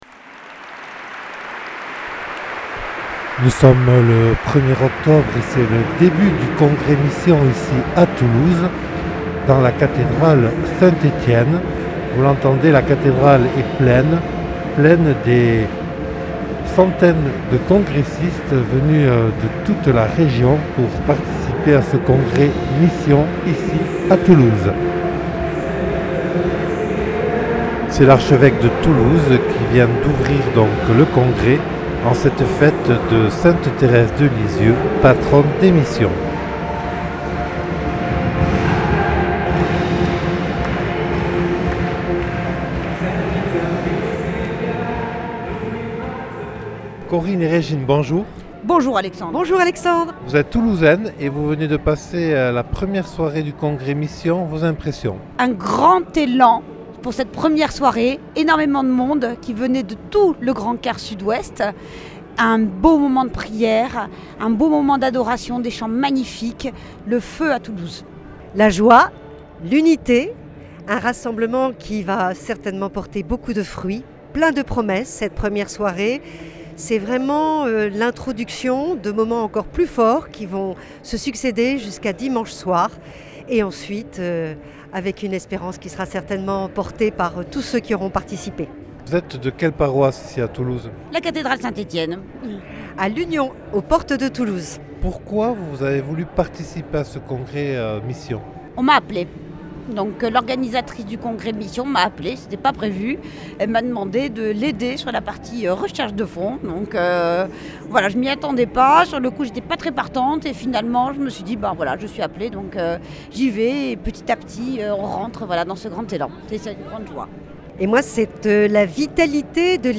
Reportage réalisé au congrès Mission à Toulouse du 1er au 3 octobre 2021.